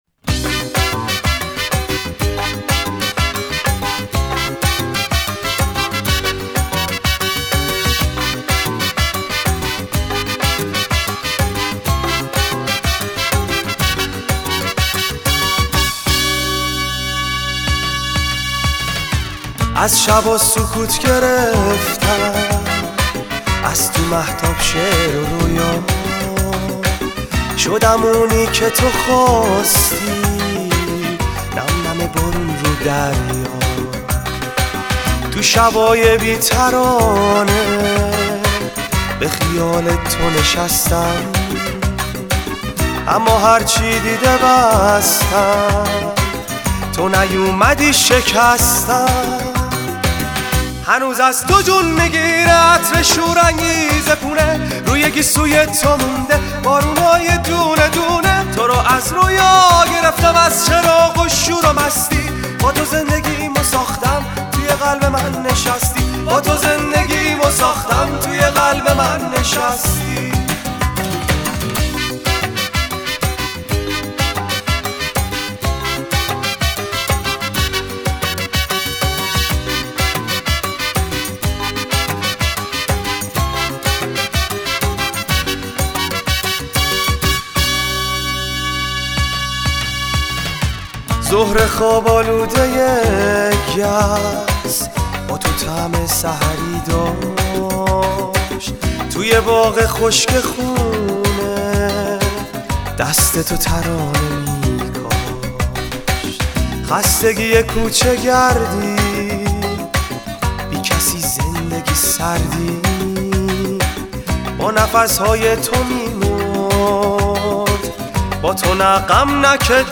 سبک : تلفیقی